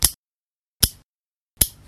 《爪切り》フリー効果音
パチッパチッパチッ、と爪を切る効果音
cut-nails.mp3